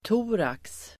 Ladda ner uttalet
thorax.mp3